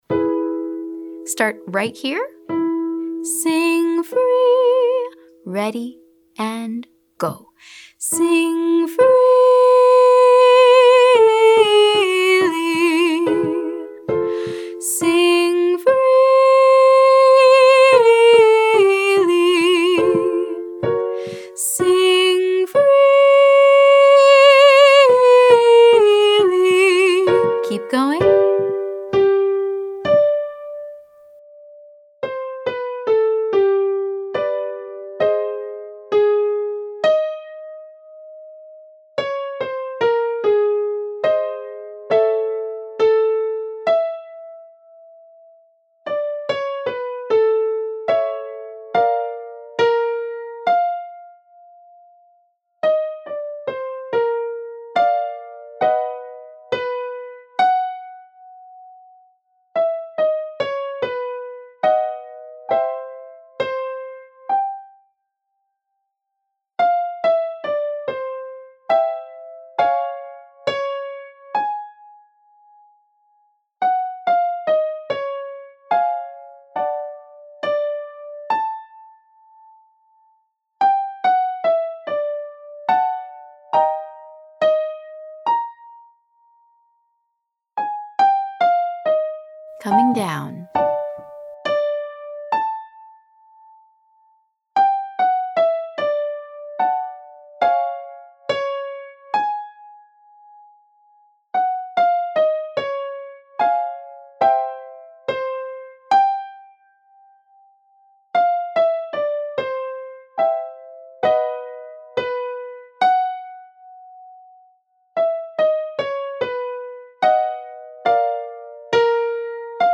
Day 7: High Head Voice Extension
Exercise 2: Sing Freely 1 5–4321 ( C )